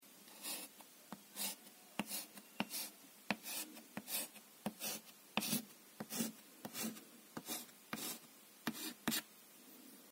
На этой странице вы найдете подборку звуков мела, пишущего по доске.
Звук крейди на дошці